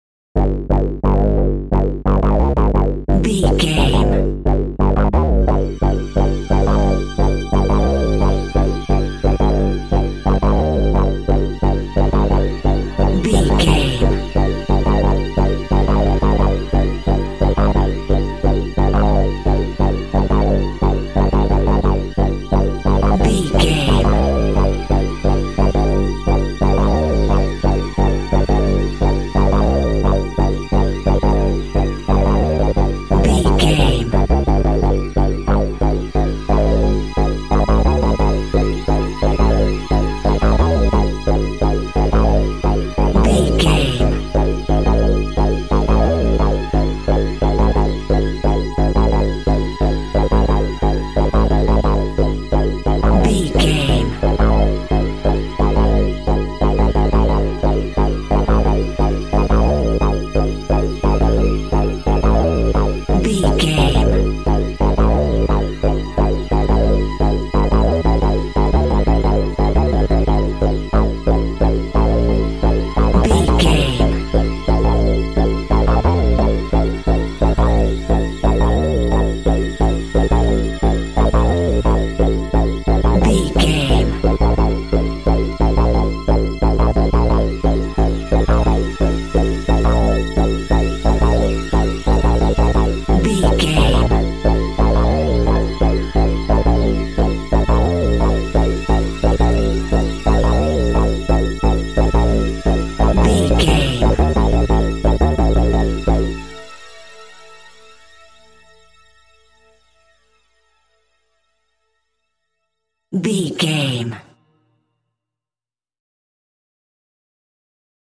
Aeolian/Minor
tension
ominous
eerie
Horror synth
Horror Ambience
synthesizer